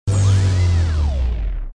equipment_cart_stop.wav